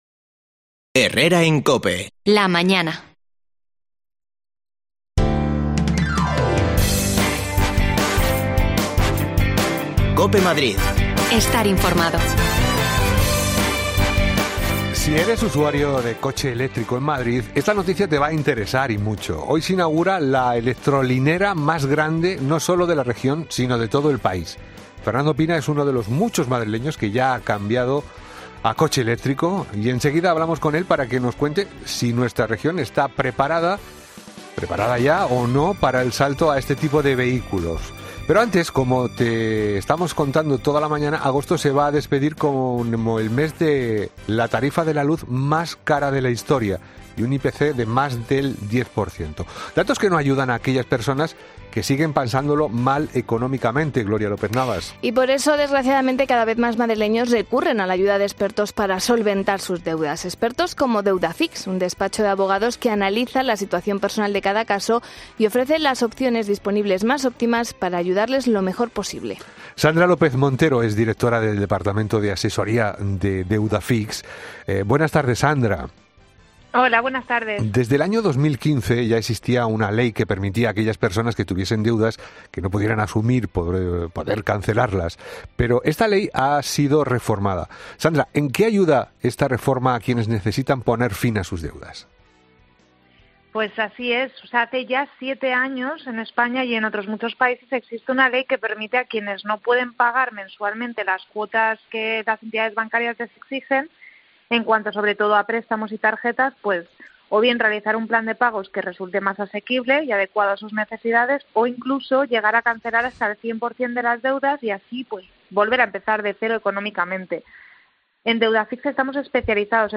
El Ayuntamiento de Madrid inaugura la electrolinera más grande de todo el país. En Herrera en COPE hemos hablado con un usuario de coche eléctrico.
Las desconexiones locales de Madrid son espacios de 10 minutos de duración que se emiten en COPE, de lunes a viernes.